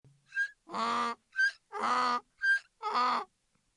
Osel
Oslík je sice občas trochu tvrdohlavý, ale hýká nádherně: „Íá íá!“
osel.mp3